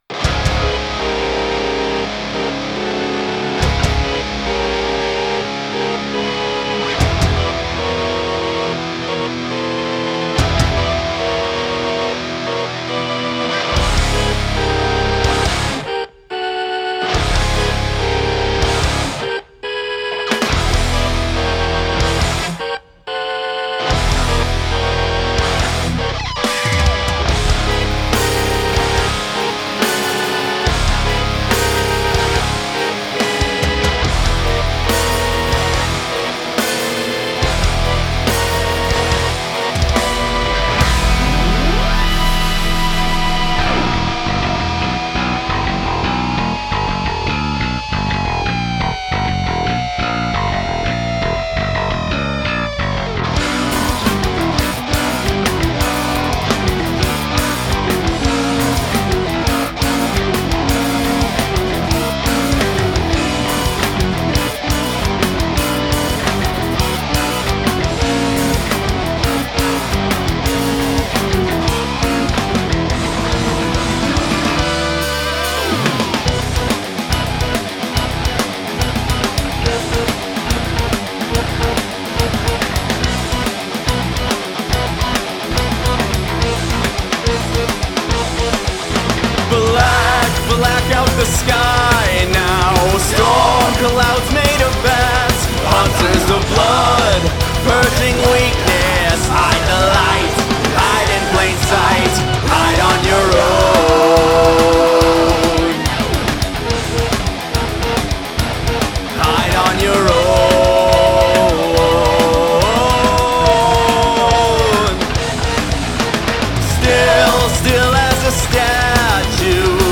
video game remix